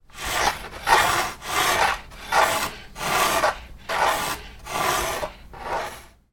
Покачивание крупной рамки для картины на стене